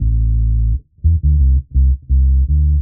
Index of /musicradar/dub-designer-samples/85bpm/Bass
DD_PBass_85_A.wav